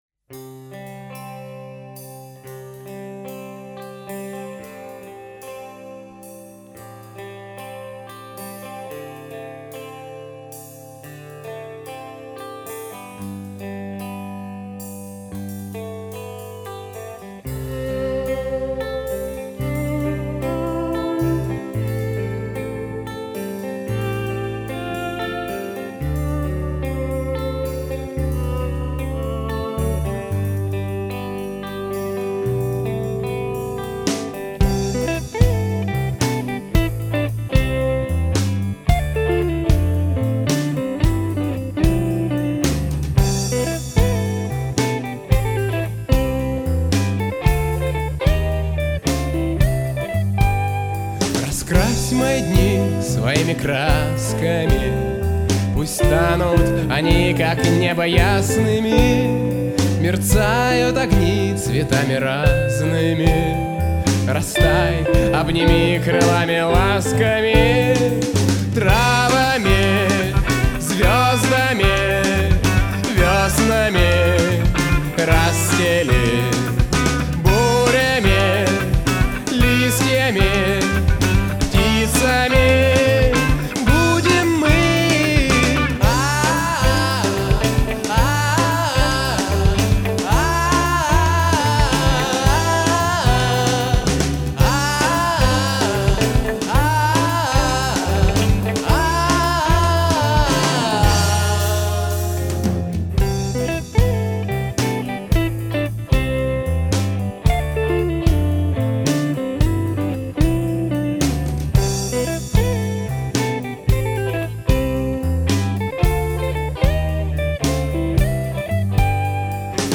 соло-гитара, ритм-гитара